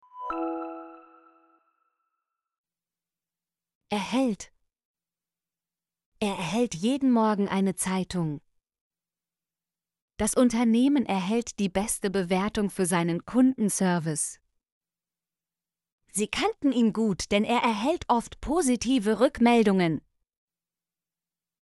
erhält - Example Sentences & Pronunciation, German Frequency List